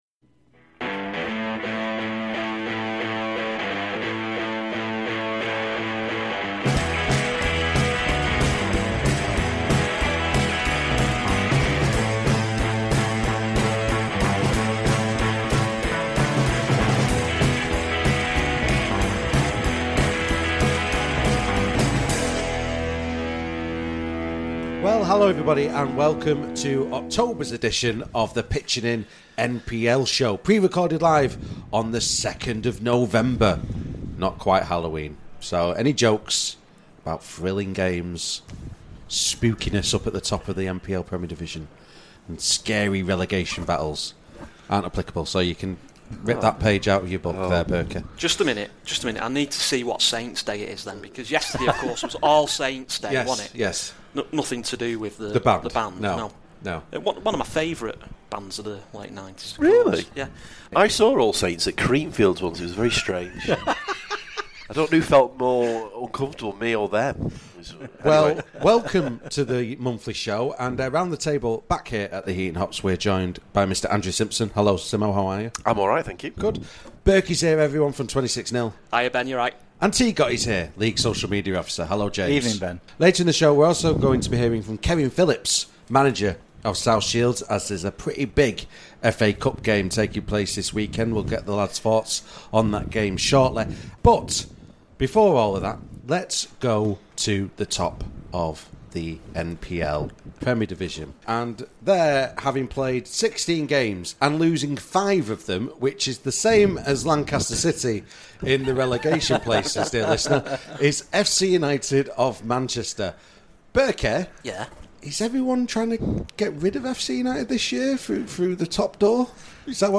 This programme was recorded at a virtual Heaton Hops on Wednesday 2nd November 2022.